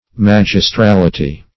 Search Result for " magistrality" : The Collaborative International Dictionary of English v.0.48: Magistrality \Mag`is*tral"i*ty\, n.; pl. -ties . Magisterialness; arbitrary dogmatism.